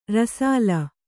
♪ rasāla